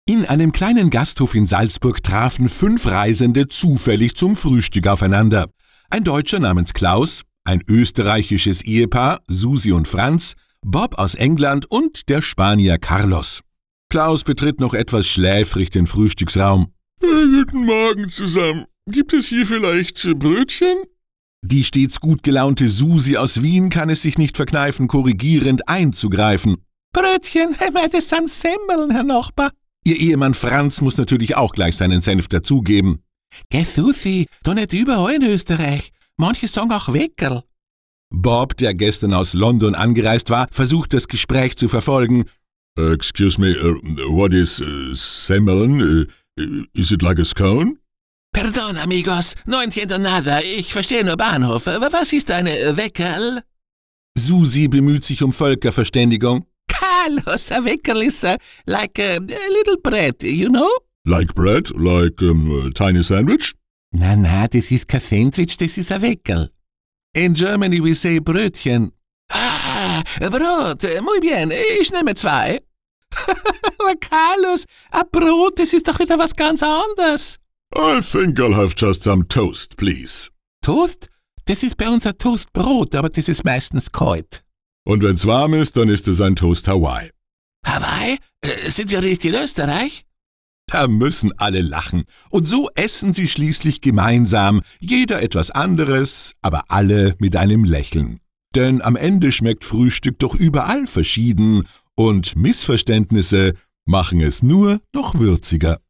Sprechprobe: Sonstiges (Muttersprache):
German voice artist for Radio, TV, Audio-Book, Commercials, E-Learning